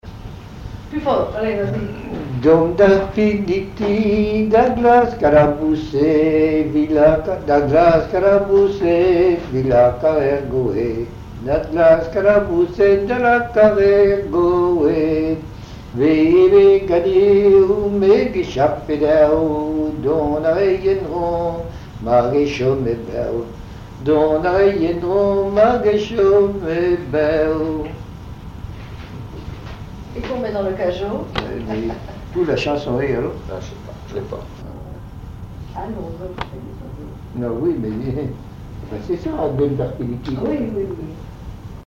Mémoires et Patrimoines vivants - RaddO est une base de données d'archives iconographiques et sonores.
chant en breton